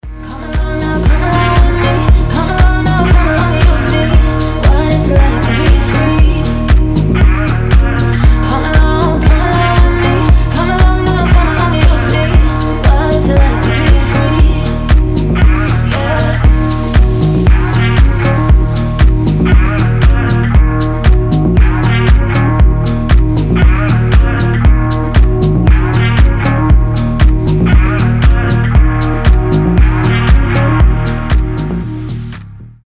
• Качество: 320, Stereo
deep house
retromix
женский голос
Cover